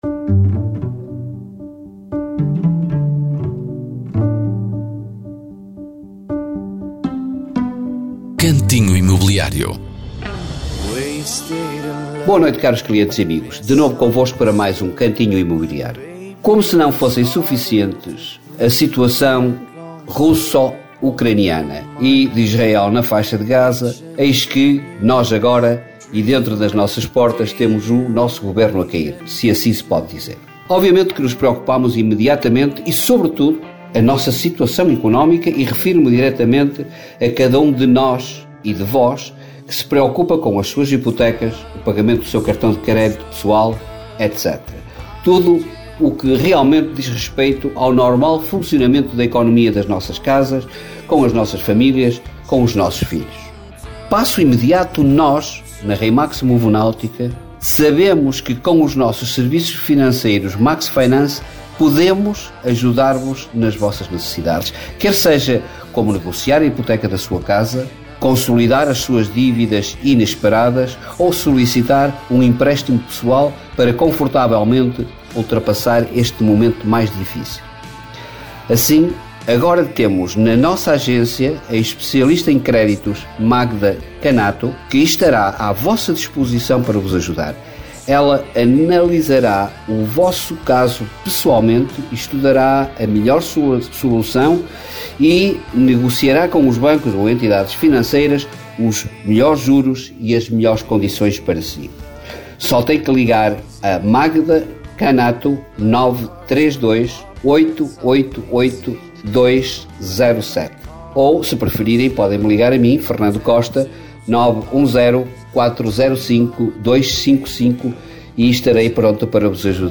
Cantinho Imobiliário é uma rubrica semanal da Rádio Caminha sobre o mercado imobiliário.